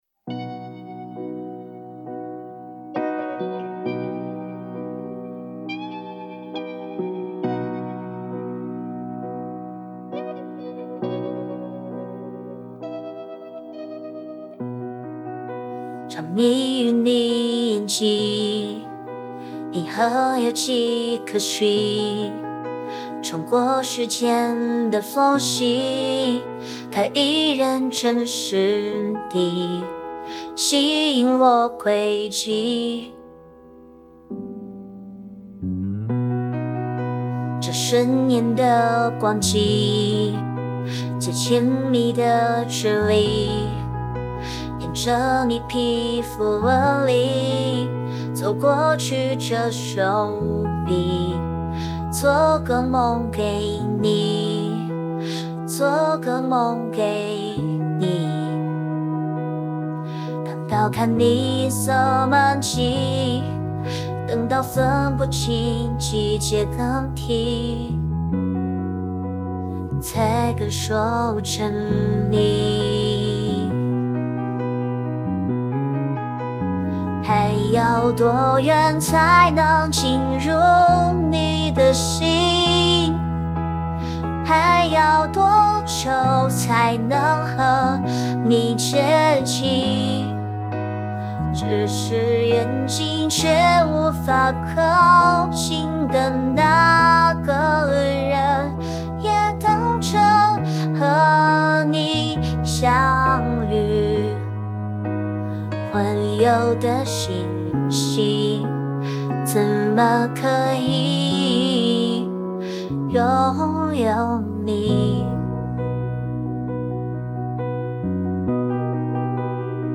RVC声音模型|喜羊羊声音模型
喜羊羊的声音特点主要体现为甜美、活泼、灵动，同时也有一些明亮而又有力的独特高音。这种声音可以让人感受到喜羊羊的活力、快乐和机智。